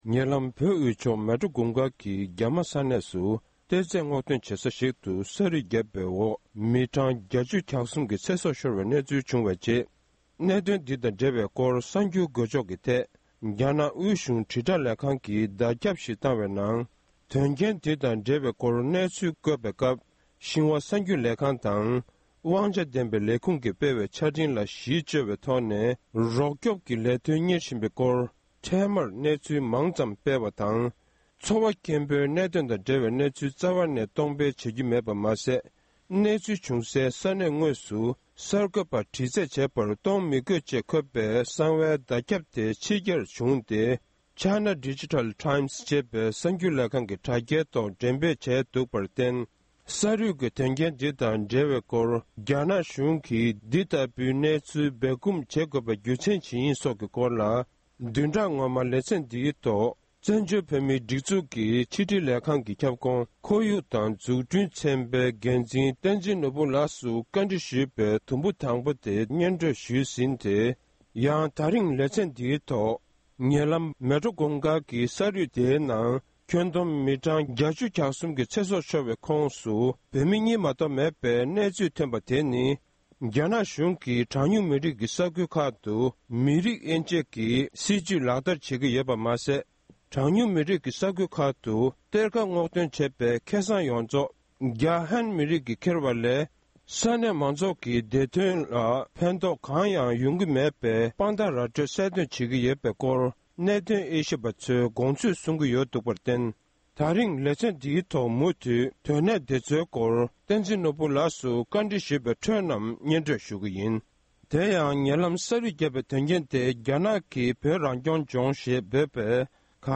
གནས་འདྲི་ཞུས་པར་མུ་མཐུད་ནས་གསན་རོགས